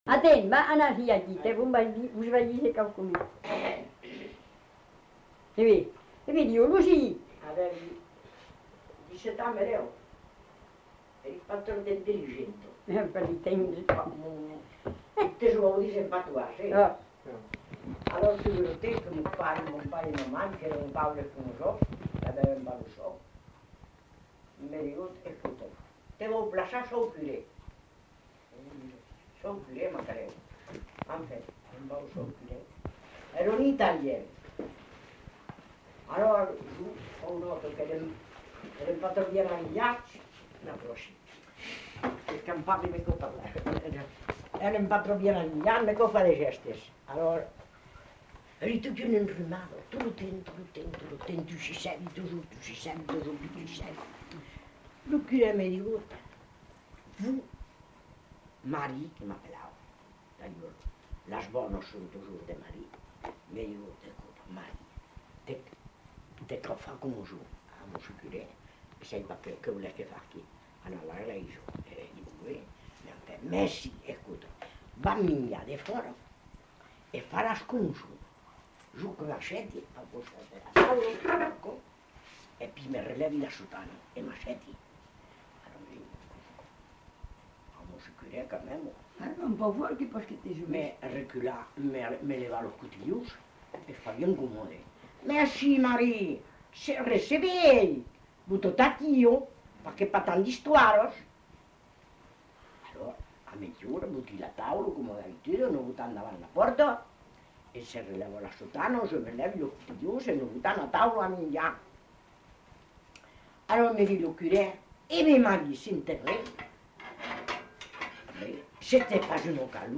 Aire culturelle : Plaine agenaise
Lieu : Passage d'Agen (Le)
Genre : conte-légende-récit
Effectif : 1
Type de voix : voix de femme
Production du son : parlé